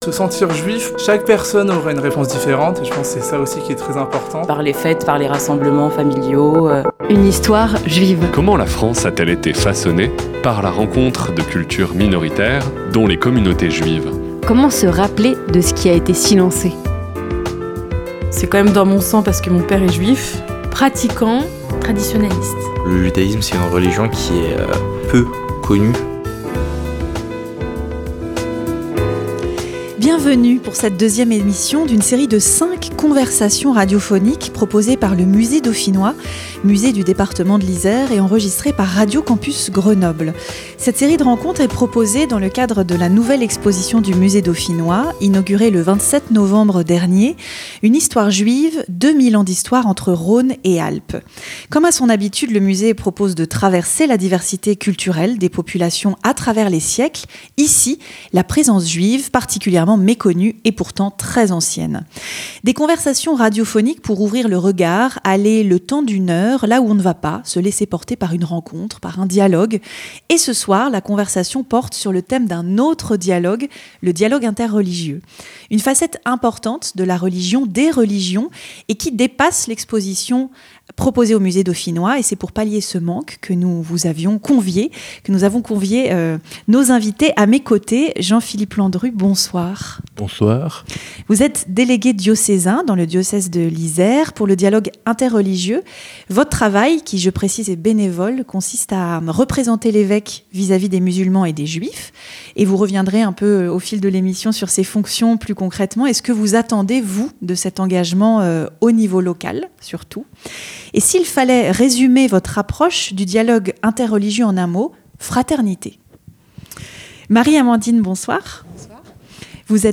Conversation radiophonique#2 - Le dialogue interreligieux - Campus Grenoble 90.8
Enregistrée au Musée Dauphinois le jeudi 22 janvier par Radio Campus Grenoble, dans le cadre de l’exposition « Une Histoire Juive »